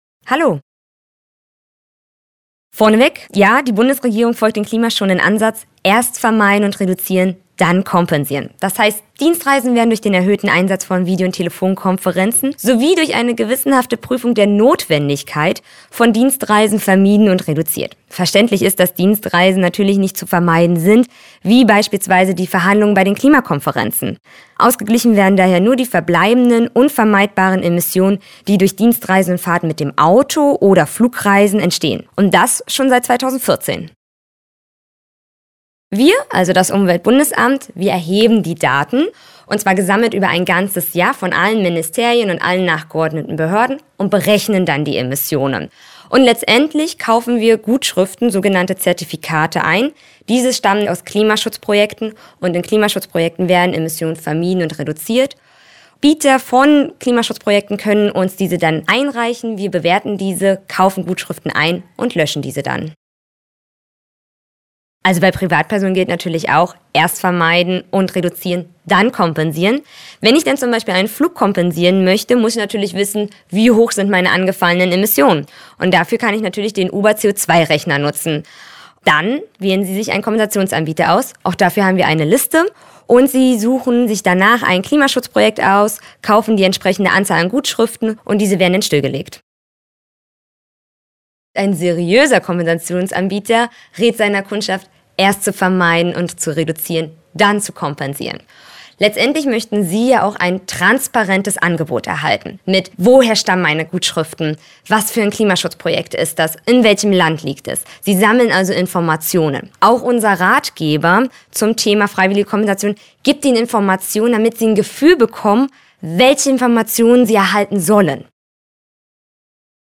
Interview: 3:11 Minuten